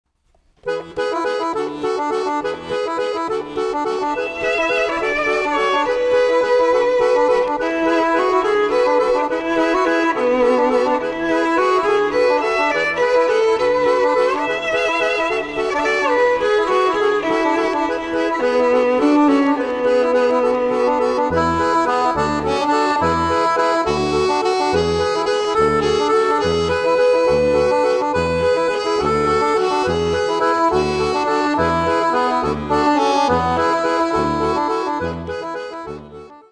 Instrumentalfassung zum Mitsingen, Mithören und Mittanzen